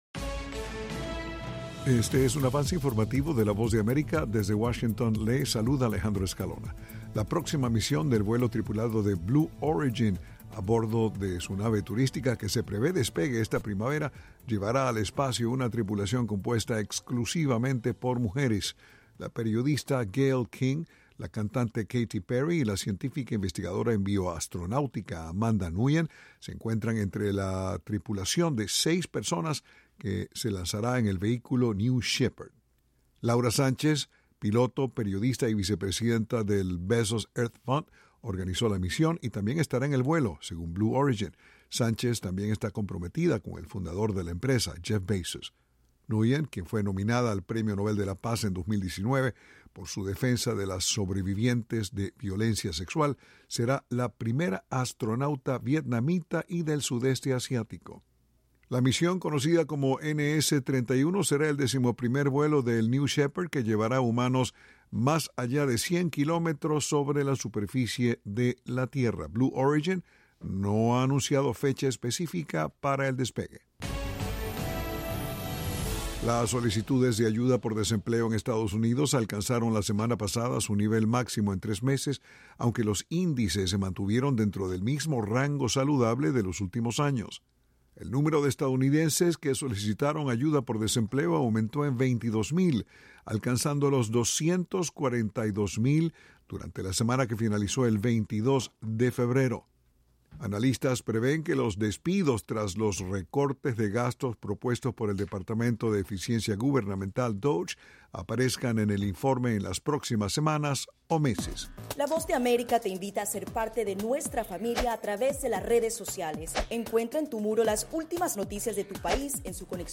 Avance Informativo
El siguiente es un avance informativo de la Voz de América.